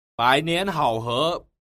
Bǎinián hǎohé
bải nén hảo khứa